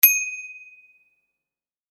機械・乗り物 （94件）
自転車ベル1.mp3